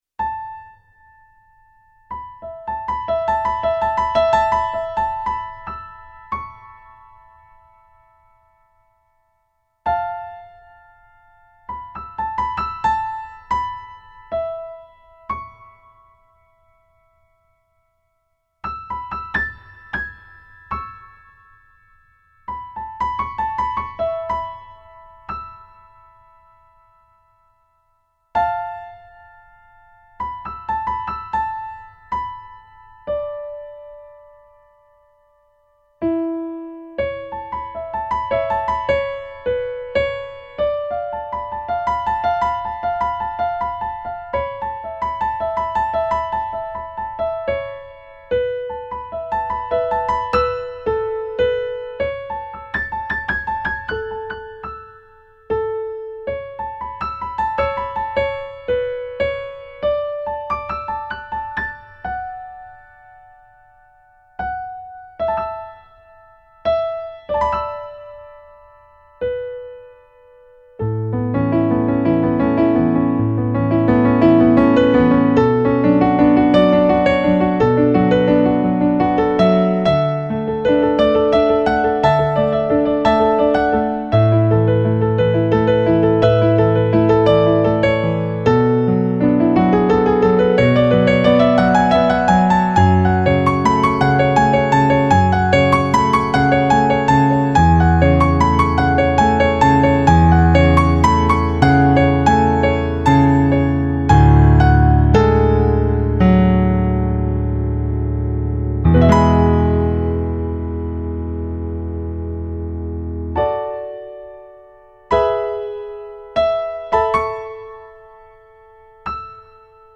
eight piano solo arrangements.  31 pages.
New Age remix